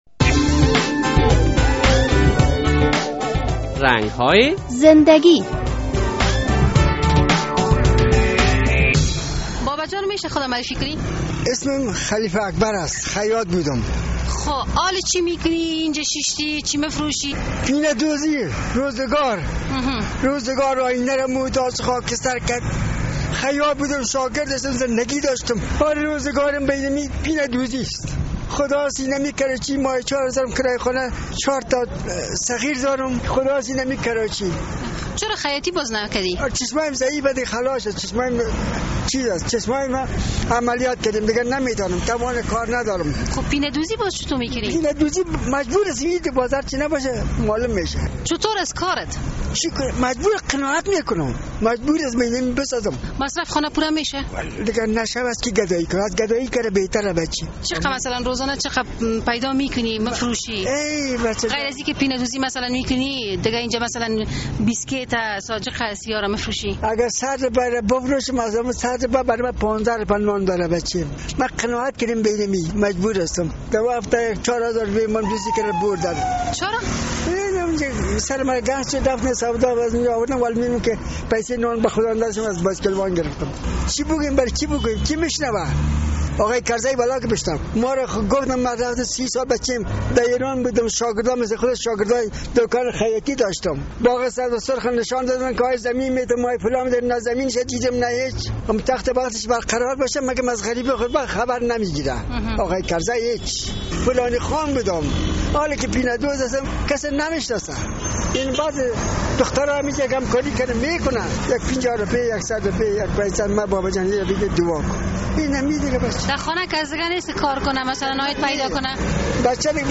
در مصاحبه با خبرنگار رادیو آزادی